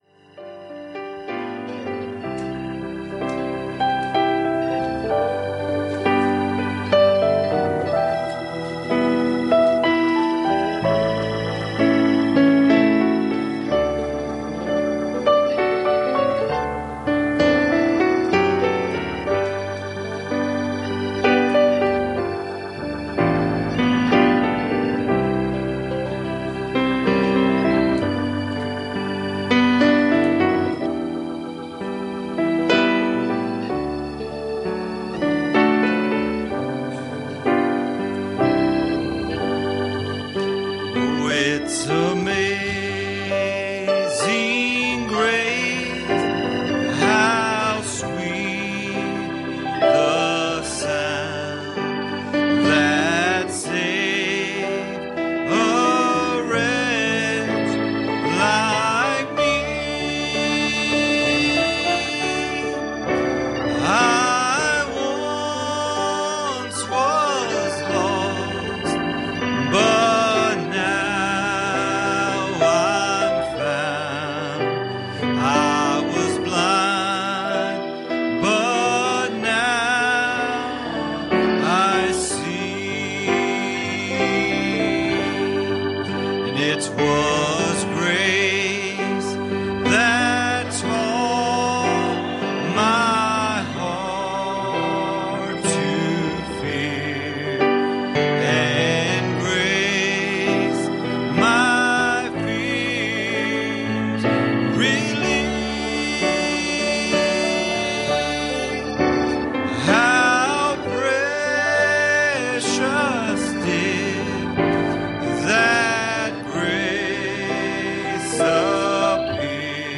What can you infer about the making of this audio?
Passage: Luke 15:3 Service Type: Wednesday Evening